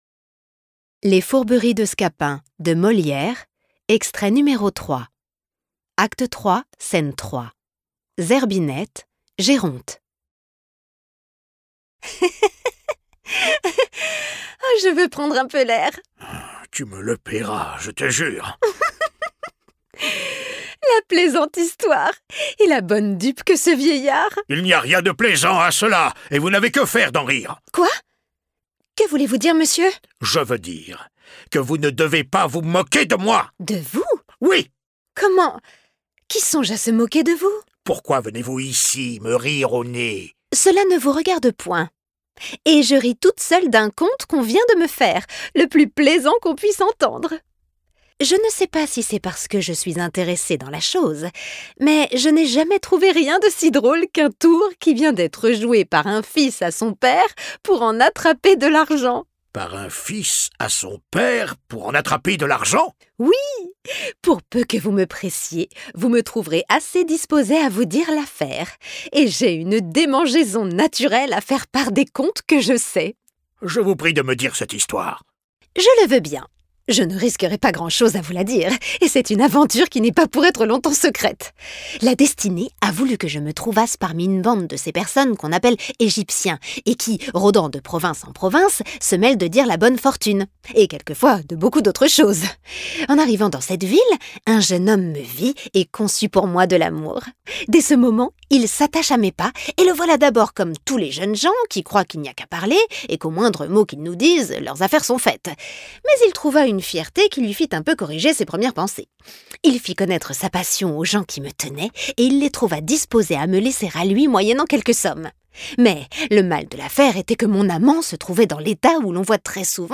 Les Fourberies de Scapin, de Molière • Acte III, scène 3 • lignes 1 à 89 (3e extrait lu)